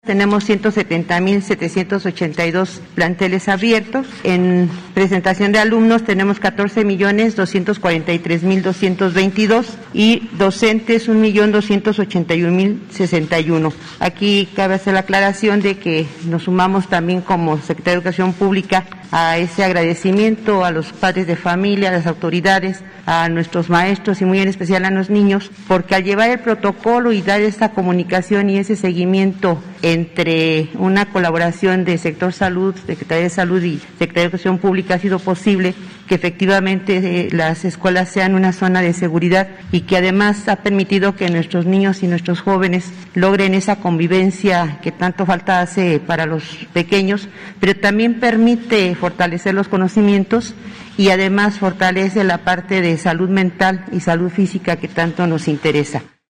En conferencia de prensa matutina que encabeza el presidente Andrés Manuel López Obrador, la secretaria detalló que este retorno a las aulas también ha fortalecido la salud mental física de los estudiantes.